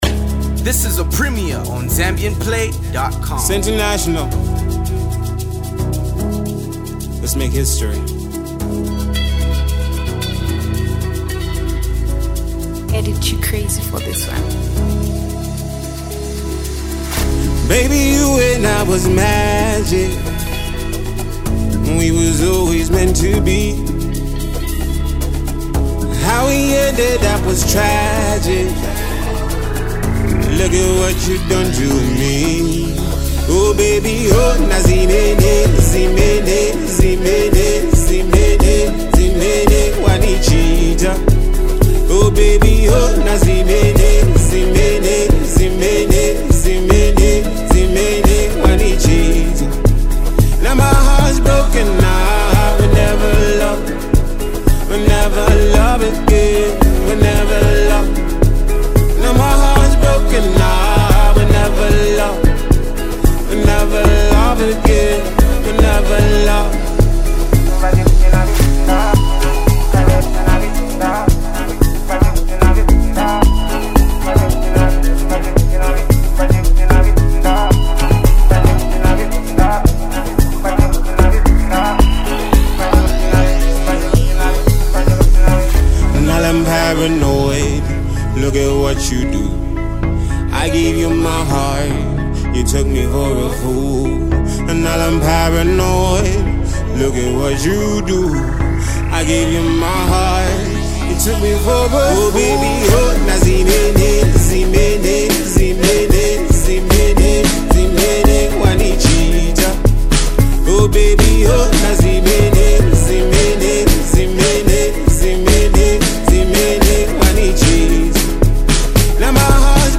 infectious and upbeat love-themed single